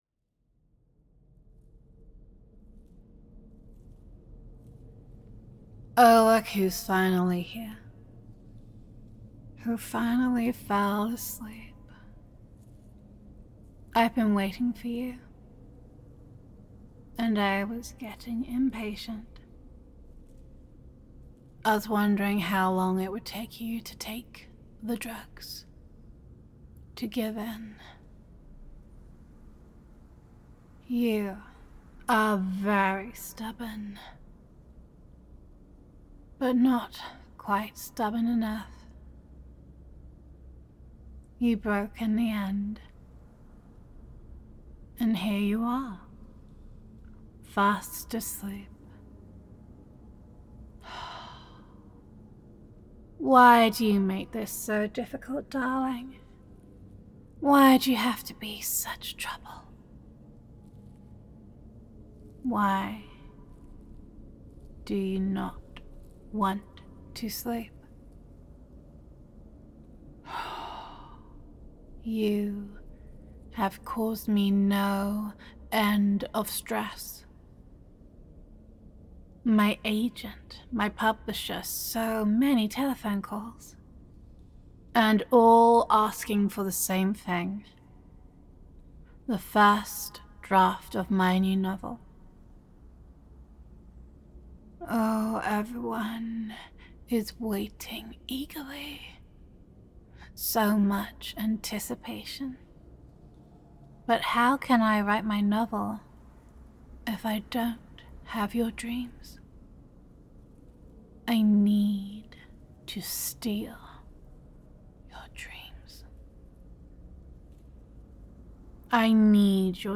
[F4A] A Captive Muse [Dreamy Thief][Dark Secrets][Inspiration][You Are My Muse][Whether You Like It or Not][Deadlines][Gender Neutral][The Woman of Your Dreams Is as Dark as You Are]